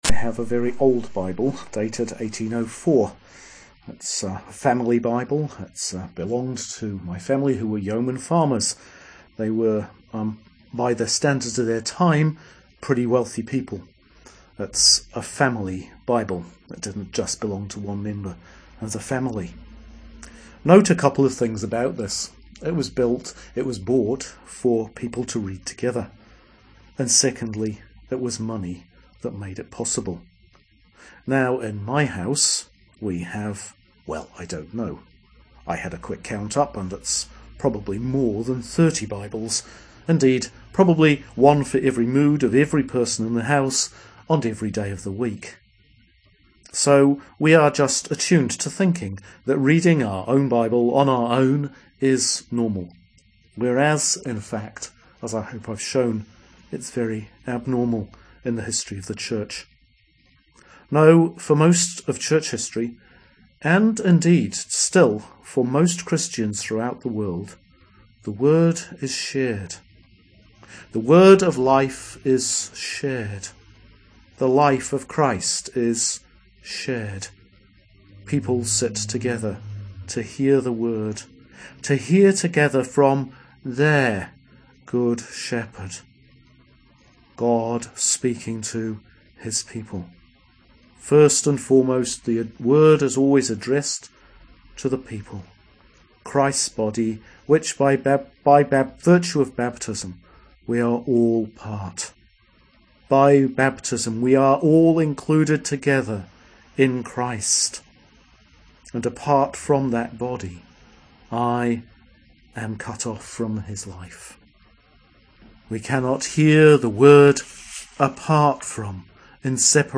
Sermon for Easter 4 – Sunday 21st April 2013 – Year C